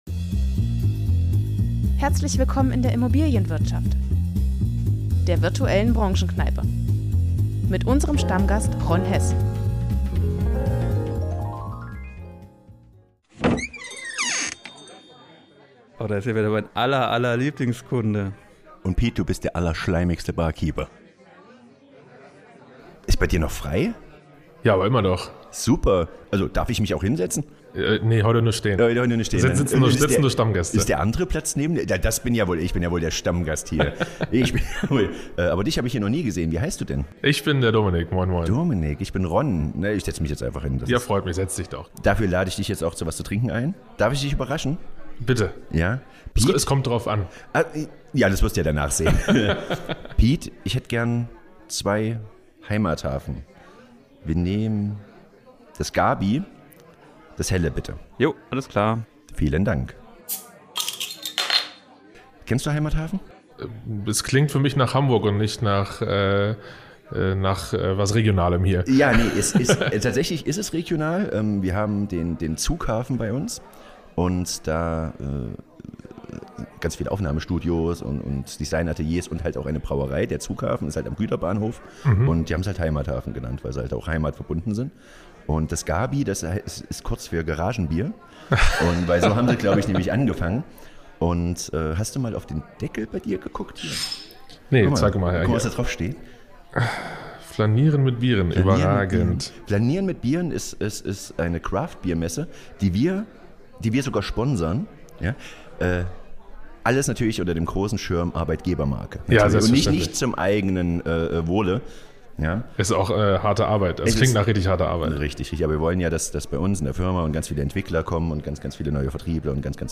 Die Soundeffekte kommen von Pixabay.